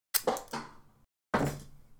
#8 Thump
Bonk Hit Impact Metallic Thump sound effect free sound royalty free Memes